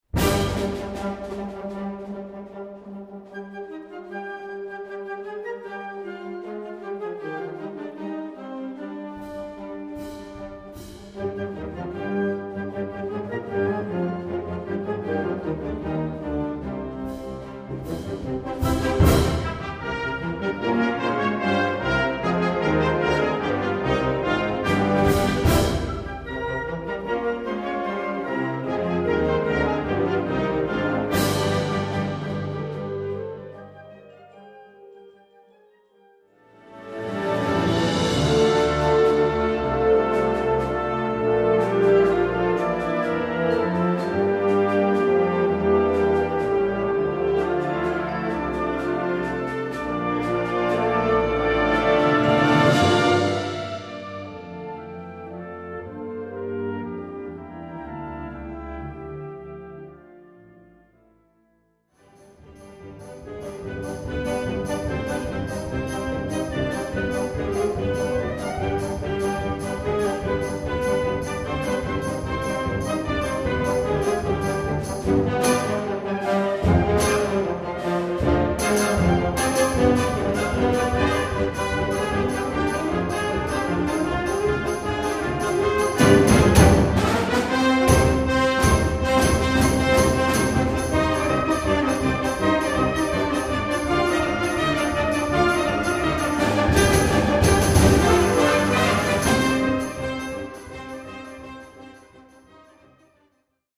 Gattung: Konzertwerk
23 x 30,5 cm Besetzung: Blasorchester Zu hören auf